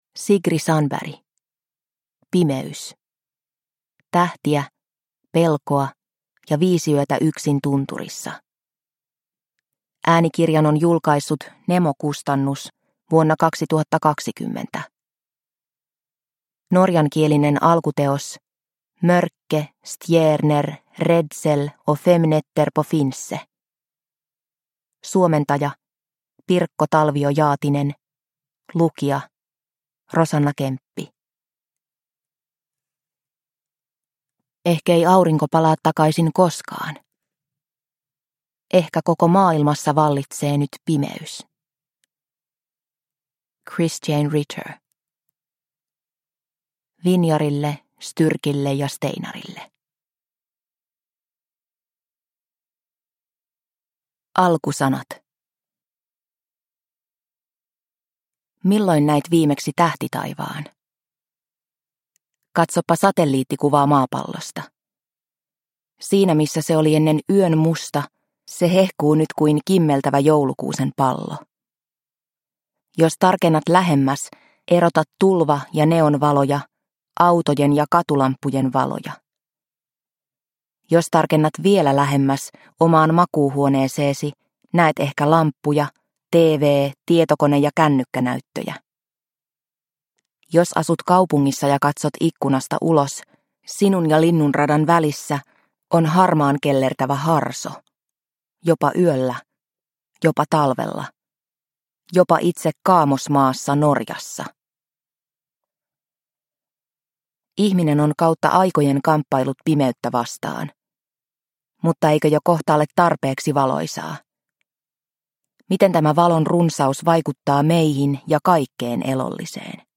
Pimeys – Ljudbok – Laddas ner